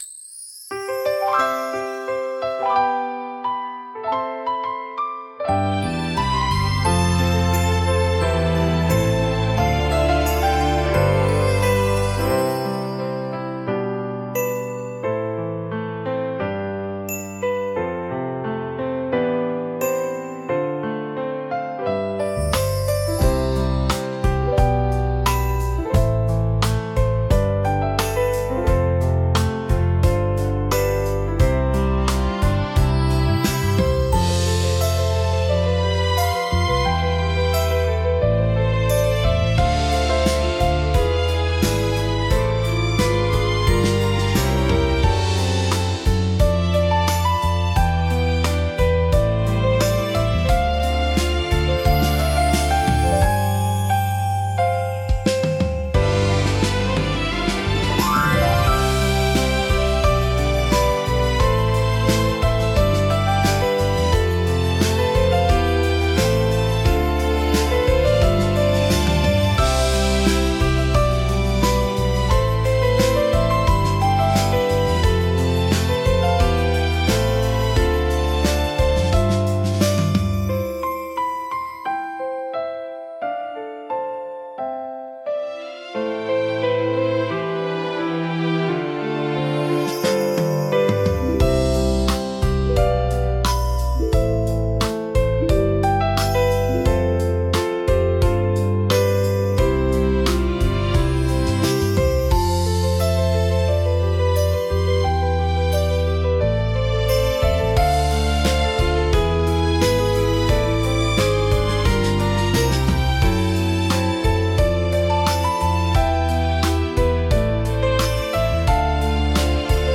明るさと元気さで、場の雰囲気を一気に盛り上げ、聴く人にポジティブな感情を喚起します。